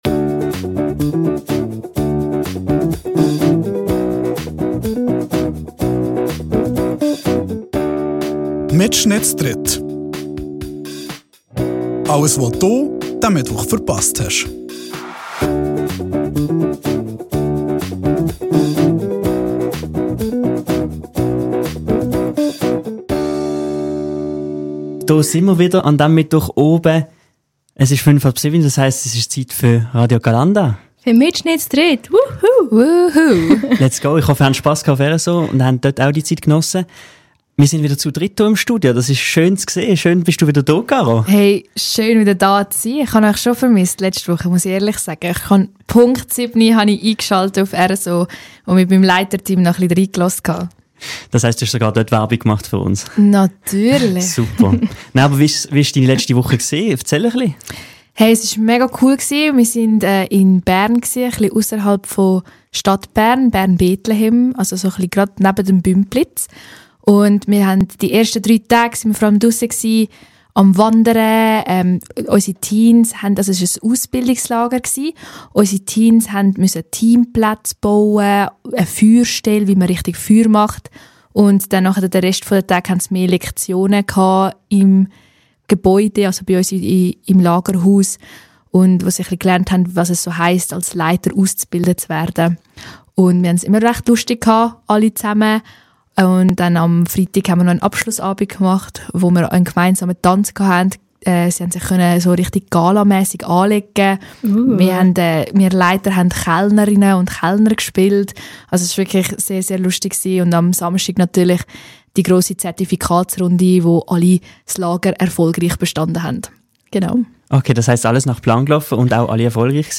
In dere Sendig sin mir wieder z dritt und rede über Herbstwanderweg und s ahstehende Big Air. Es werde aber au sehr vieli Entscheidige troffe, au wenn mir nid alli Froge beantworte chönne.